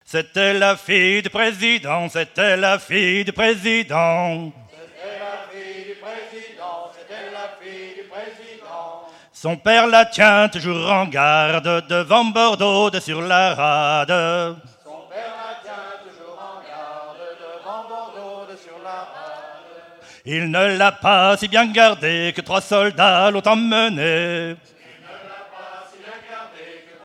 Genre strophique
Festival de la chanson traditionnelle - chanteurs des cantons de Vendée
Pièce musicale inédite